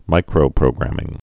(mīkrō-prōgrăm-ĭng, -grə-mĭng)